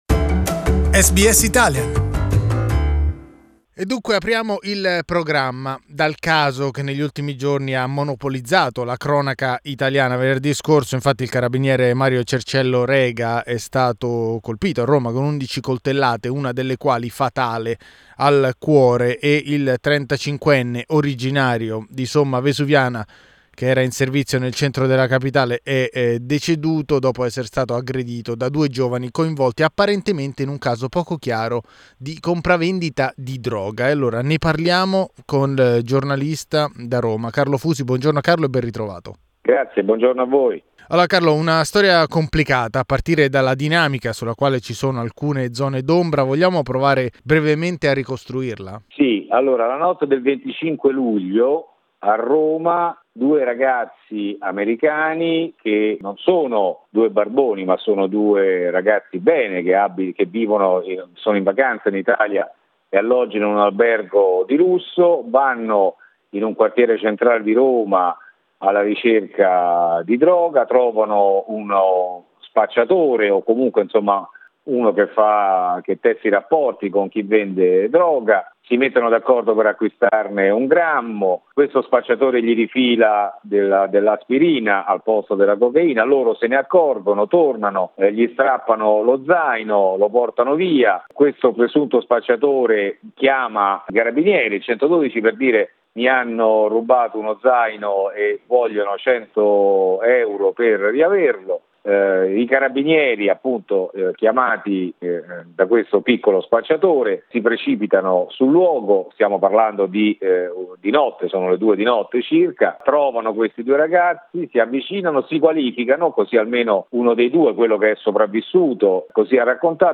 reports from Rome.